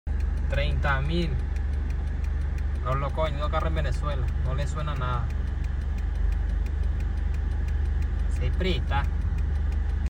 You Just Search Sound Effects And Download. tiktok laughing sound effects Download Sound Effect Home